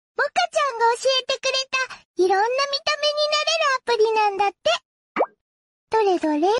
Funny Sound Suits To Sakuna sound effects free download